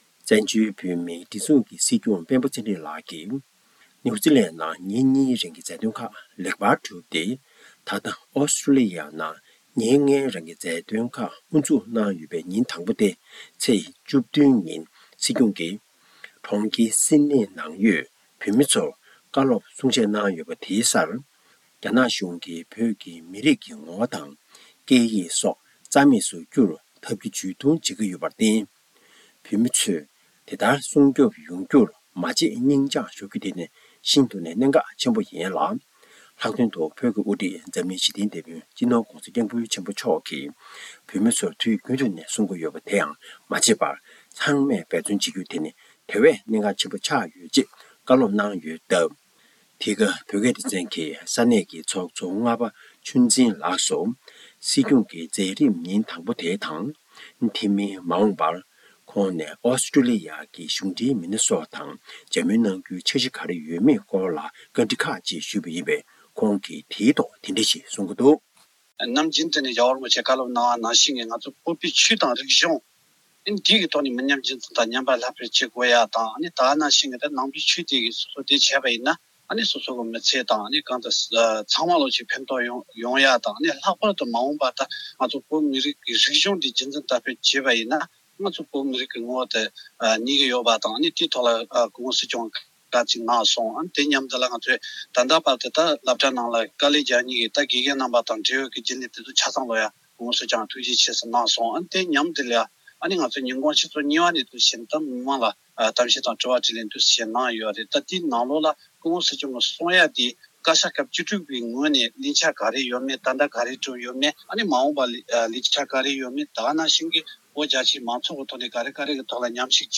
ས་གནས་ཀྱི་འབྲེལ་ཡོད་མི་སྣར་བཀའ་འདྲི་ཞུས་ཡོད།